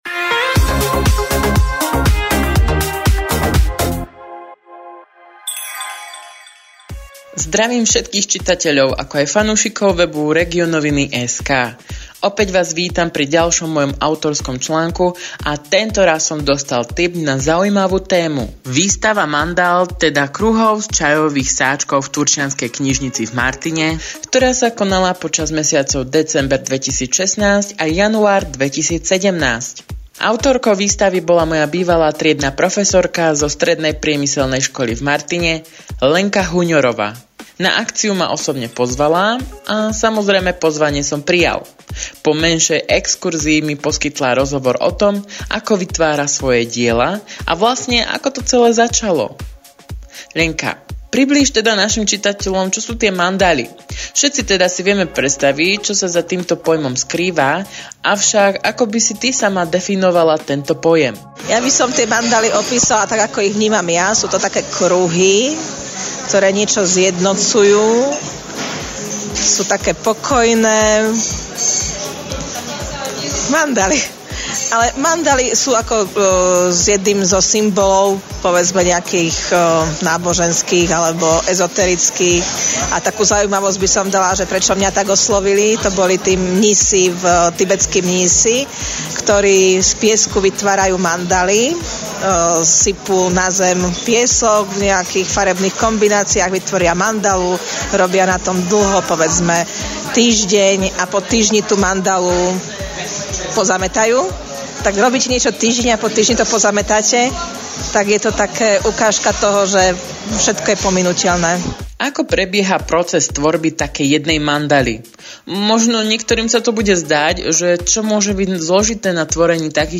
v nasledujúcom rozhovore priblížila prečo sa začala zaujímať o mandaly, ako tvorí svoje diela a koľko času venovala tomuto koníčku.